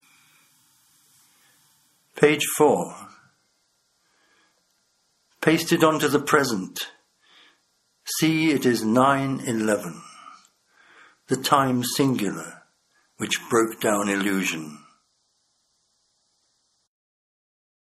Read by Tom Phillips